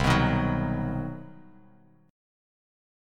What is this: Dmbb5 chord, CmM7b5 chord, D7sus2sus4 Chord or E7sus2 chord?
CmM7b5 chord